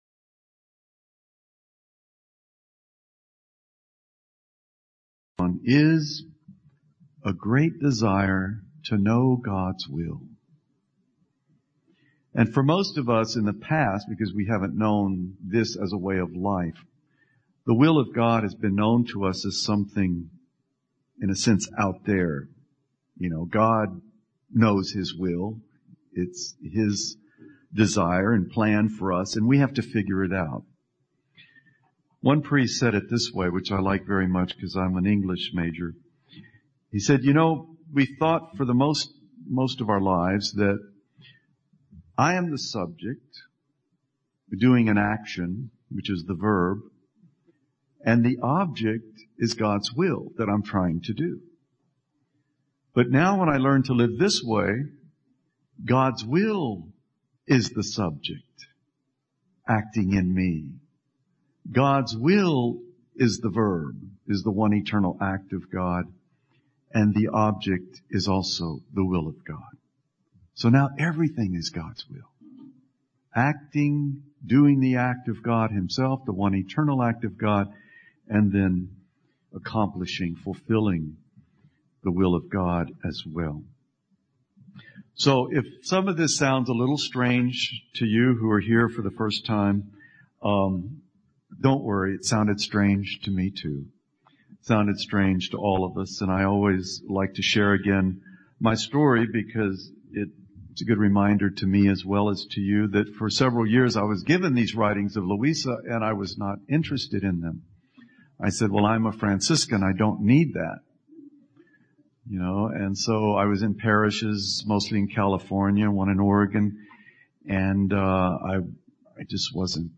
Retreat Talk #1 part 2
The second half of the first day retreat in Texas, USA.